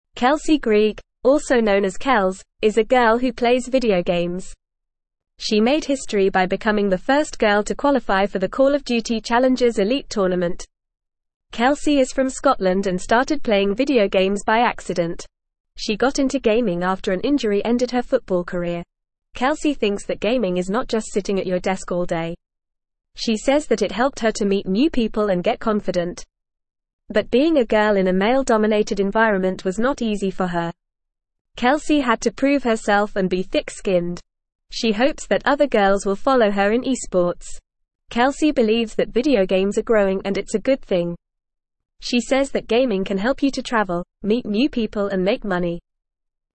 English-Newsroom-Beginner-FAST-Reading-Girl-Makes-History-in-Video-Game-Tournament.mp3